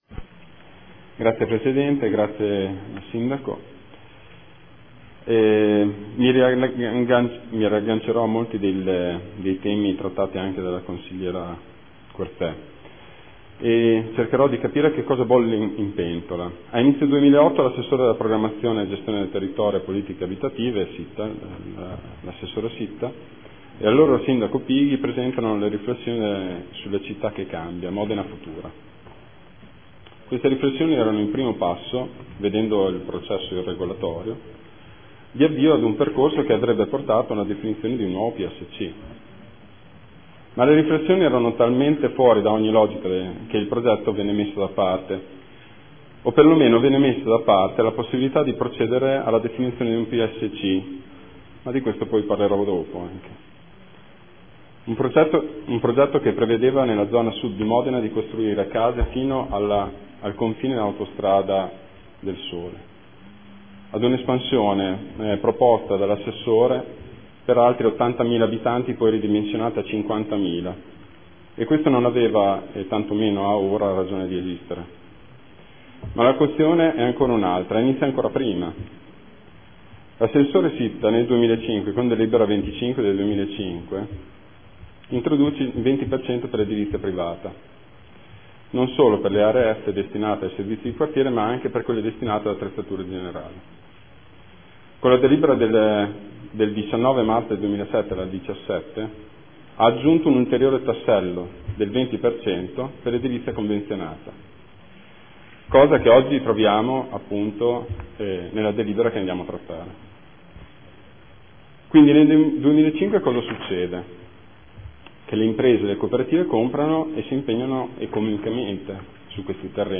Marco Bortolotti — Sito Audio Consiglio Comunale
Seduta del 13/11/2014 Dibattito.